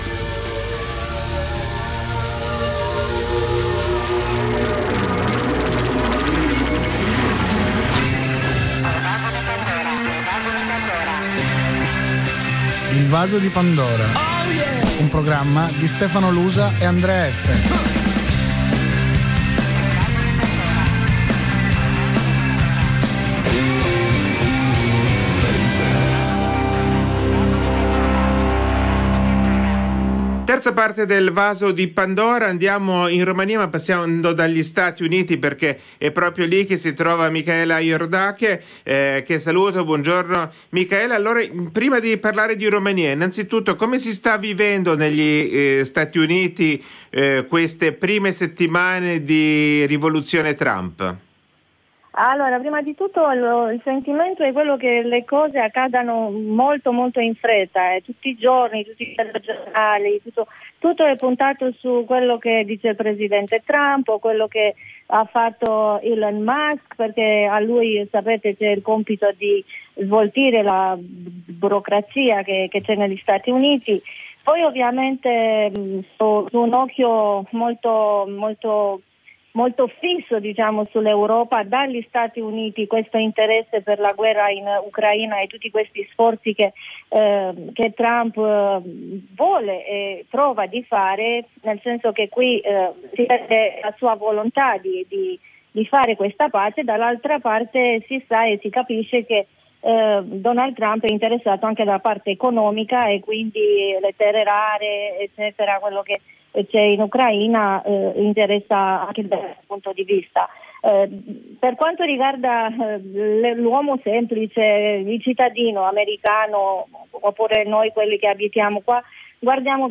in diretta alla trasmissione